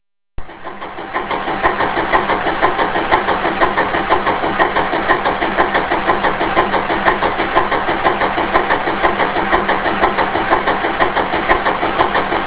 I guess they are called just "low speed diesel". My favourite is a 3-cylinder Callesen.
You are right, the Callesen has a real nice sound.
Callesen3syl.wav